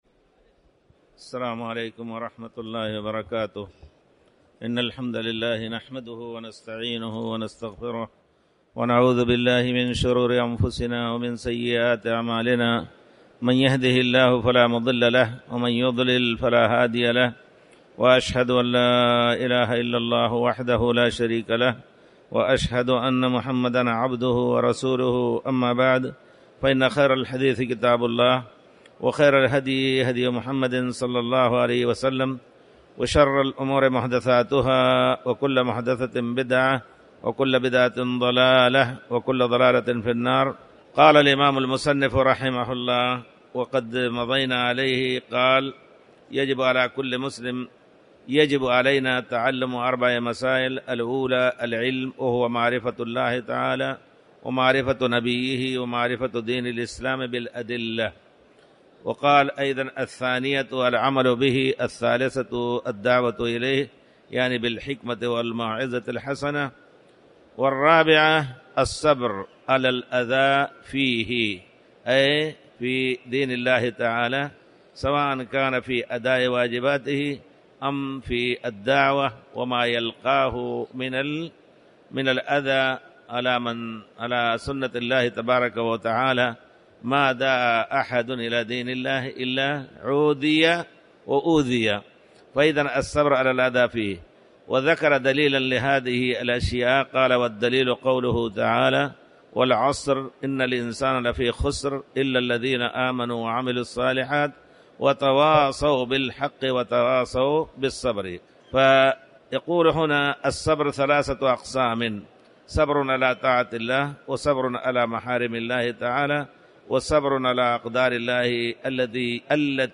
تاريخ النشر ٢٤ رمضان ١٤٣٩ هـ المكان: المسجد الحرام الشيخ